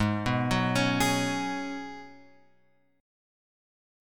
G# Minor 11th